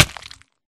hurtflesh3.ogg